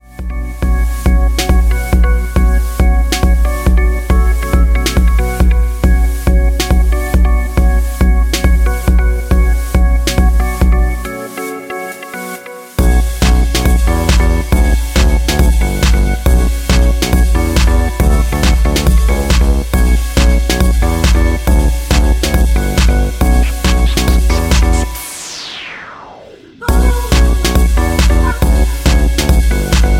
F#m
MPEG 1 Layer 3 (Stereo)
Backing track Karaoke
Pop, 2010s